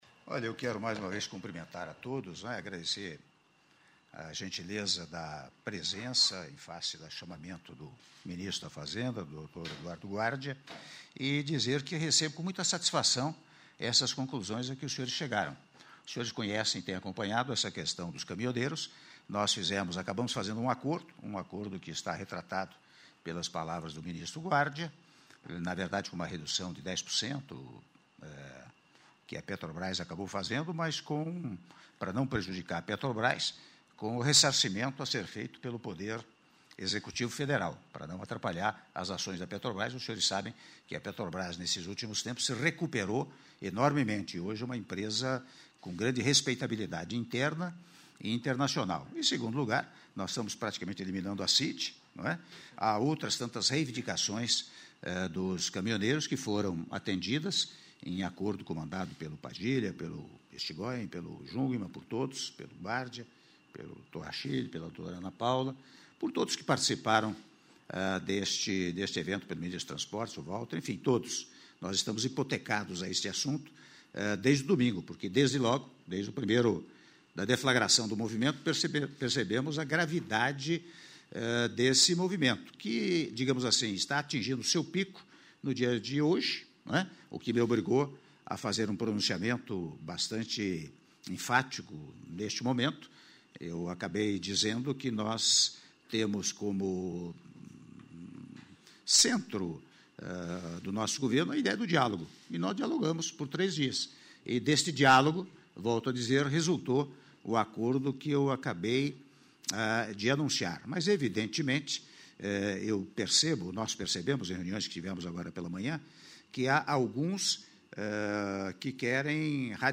Áudio do discurso do Presidente da República, Michel Temer, durante a Reunião Extraordinária do Conselho Nacional de Política Fazendária - CONFAZ- Brasília/DF- (05min44s)